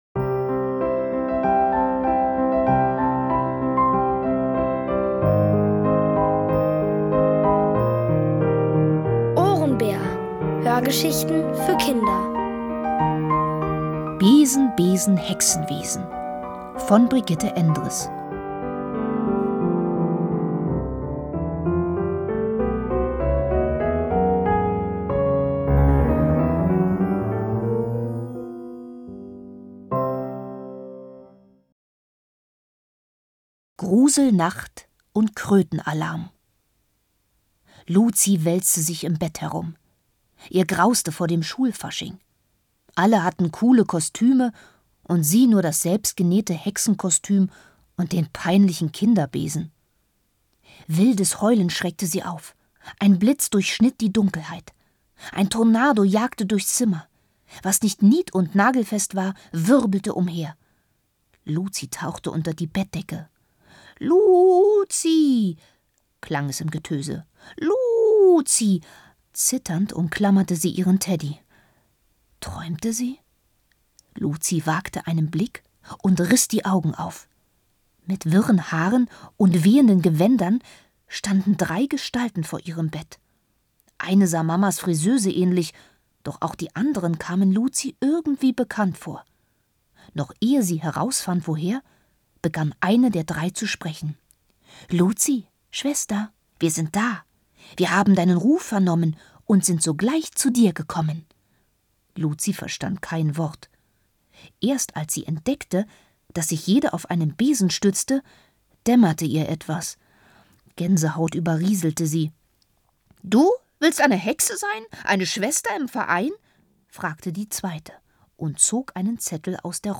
Von Autoren extra für die Reihe geschrieben und von bekannten Schauspielern gelesen.
Hörgeschichten gibt es hier: